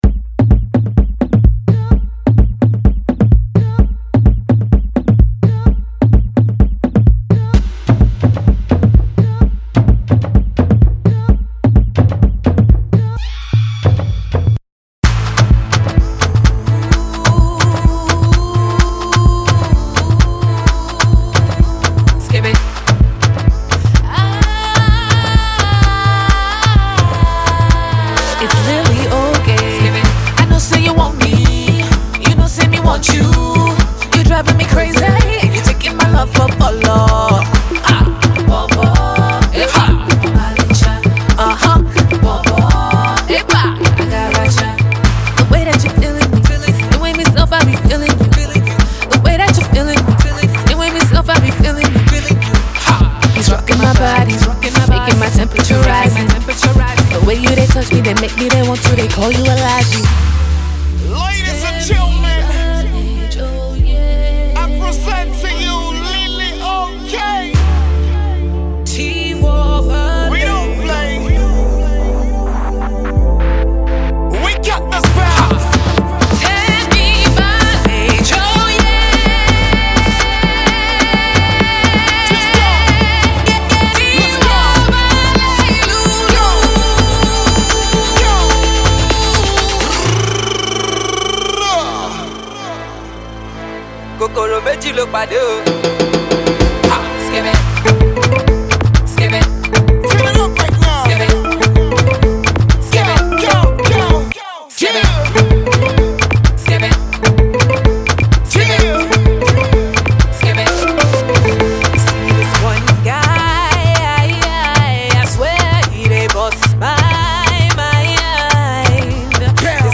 a Nigerian American Afrobeats artist
afrohouse feel